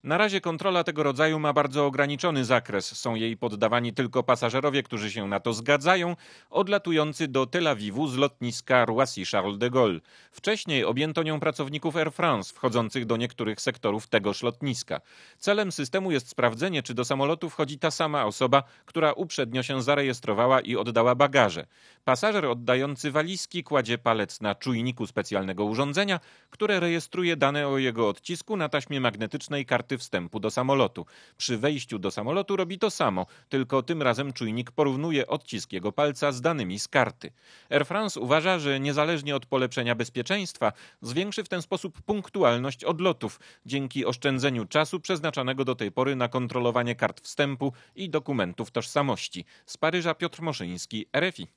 Posłuchaj relacji naszego korespondenta